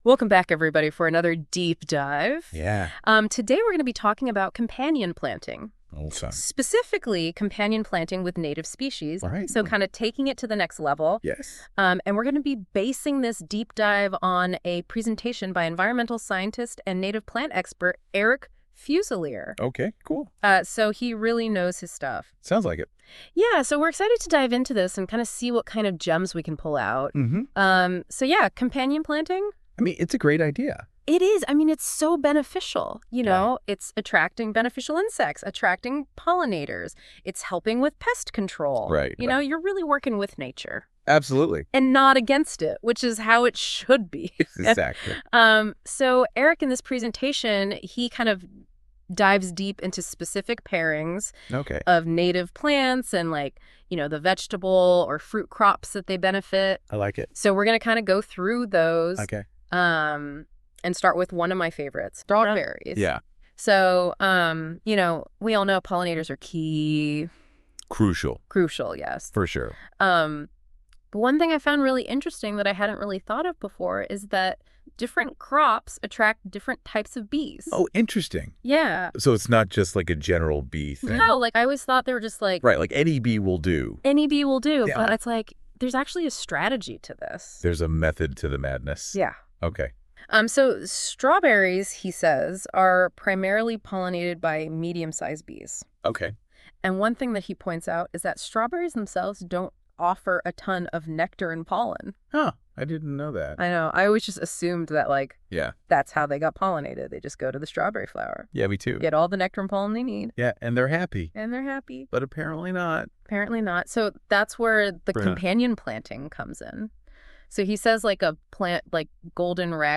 Features a presentation by an environmental scientist on companion planting using native plants. The speaker emphasizes attracting beneficial insects and pollinators to improve vegetable garden yields.